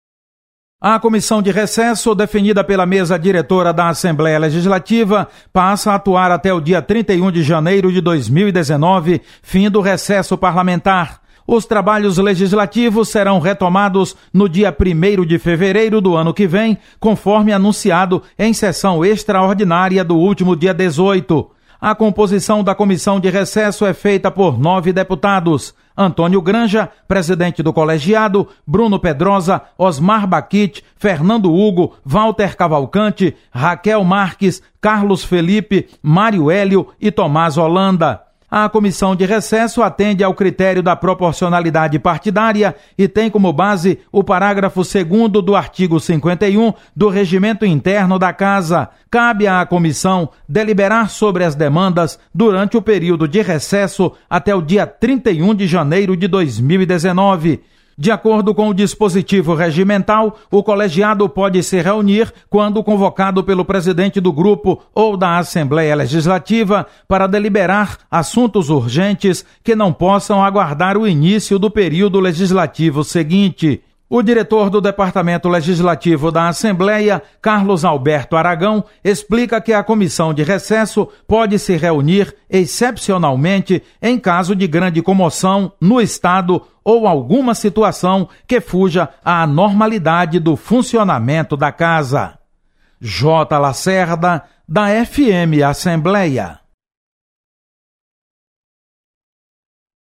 Criada comissão de recesso. Repórter